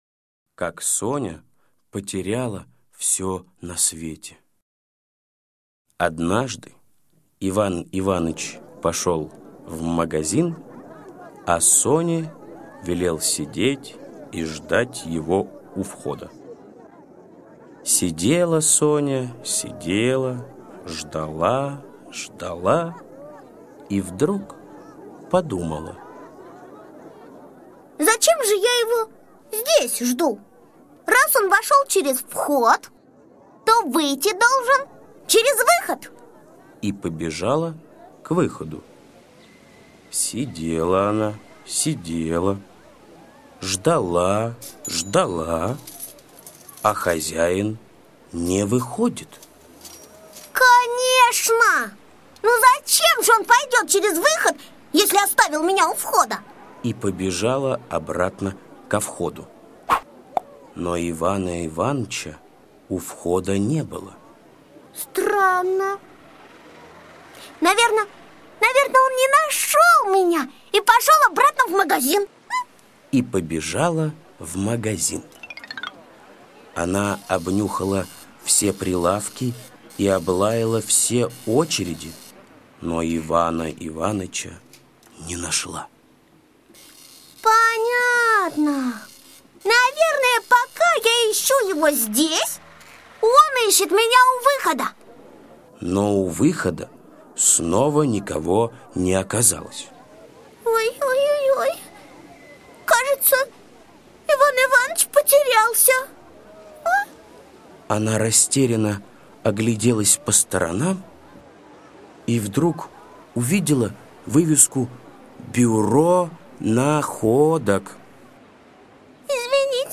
Слушайте Как Соня потеряла все на свете - аудиосказка Усачева А.А. Сказка про собачку Соню, которая потеряла хозяина и забыла свой адрес.